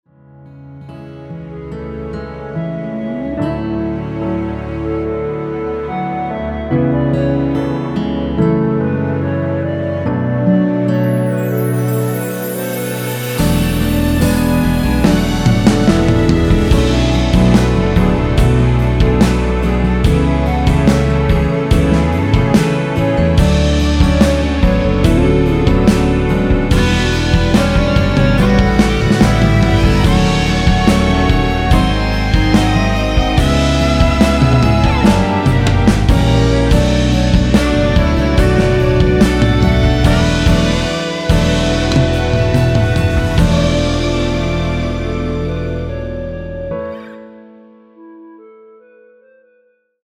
노래가 바로 시작 하는 곡이라 전주 만들어 놓았으며
원키에서(-3)내린 멜로디 포함된 1절후 후렴으로 진행되게 편곡한 MR 입니다.(미리듣기및 가사 참조)
앞부분30초, 뒷부분30초씩 편집해서 올려 드리고 있습니다.
중간에 음이 끈어지고 다시 나오는 이유는